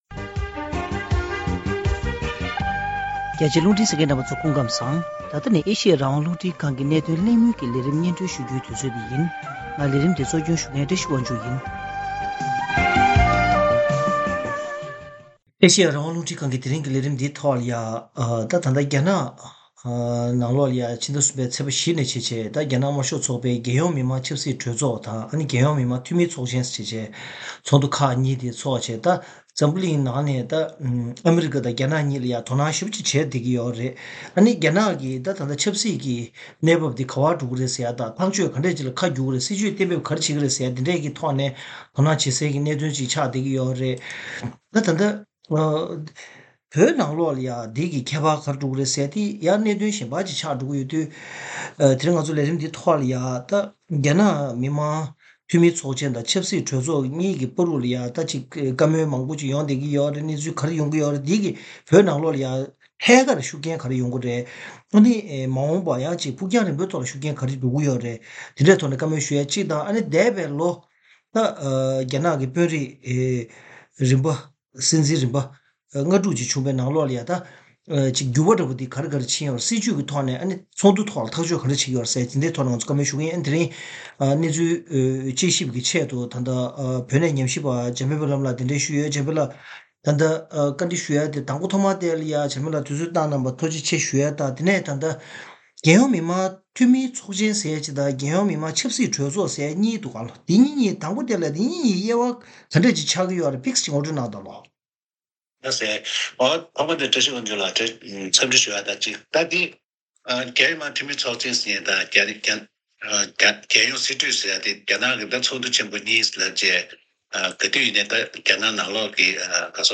ཐེངས་འདིའི་གནད་དོན་གླེང་མོལ་གྱི་ལས་རིམ་ནང་།